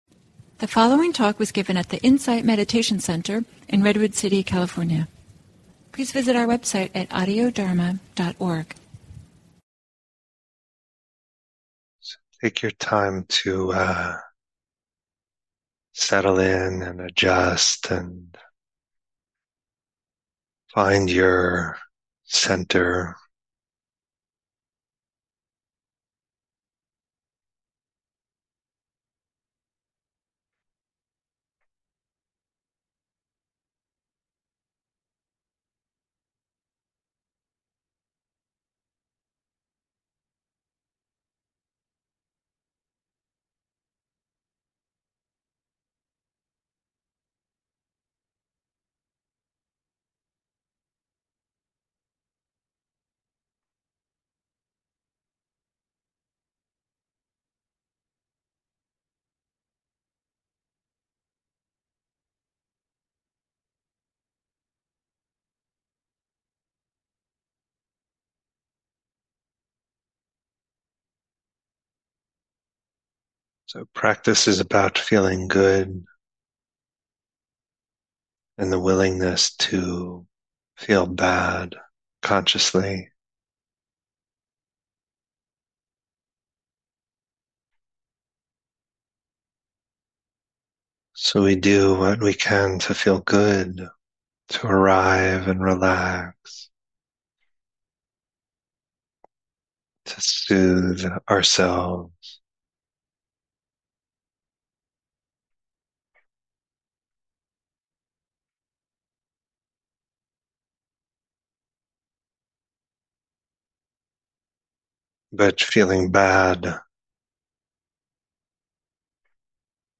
Guided Meditation: Awareness is reverential about all phenomena